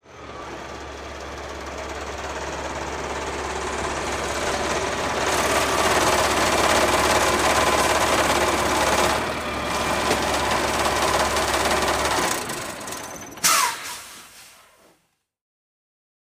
tr_sbus_driveup_01_hpx
Exterior point of view of a school bus as it drives, idles and shuts off. Vehicles, School Bus Bus, School Engine, Motor